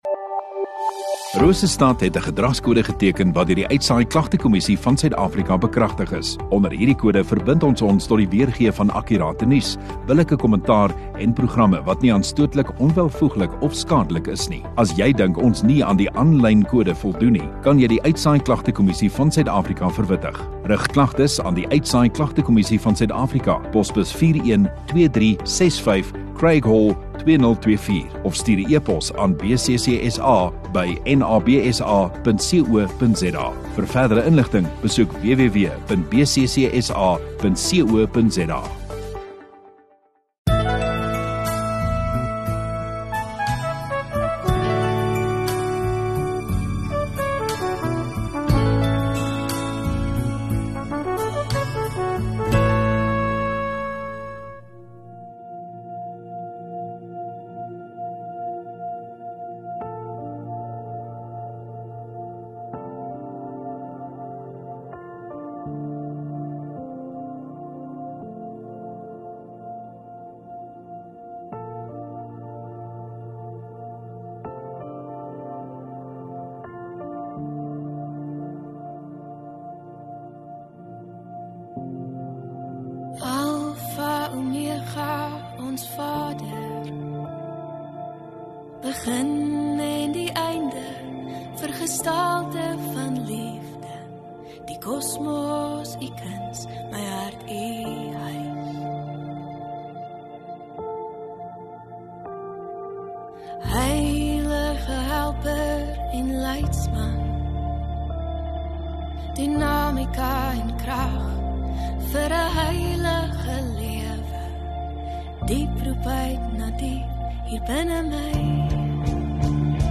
1 Jan Woensdag Oggenddiens